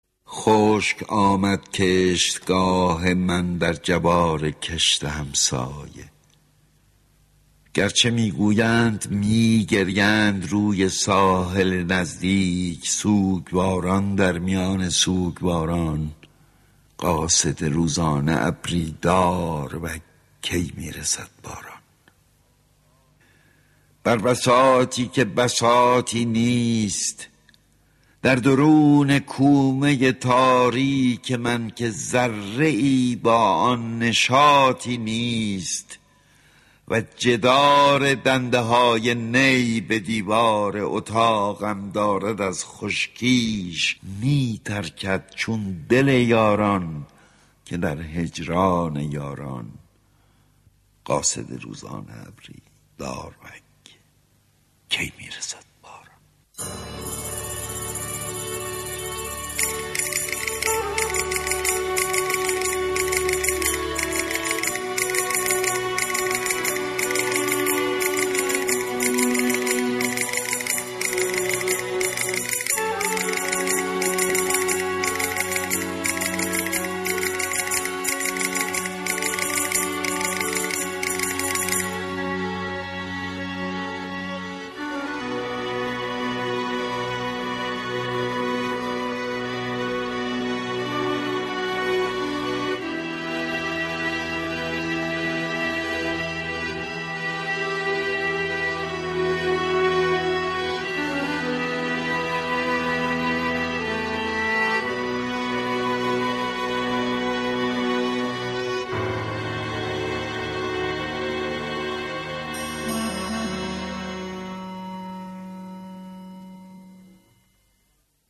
داروگ (با صدای احمد شاملو)